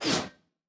|攻击音效
|初级和经典及以上形态攻击音效
HogRider_base_atk_1.wav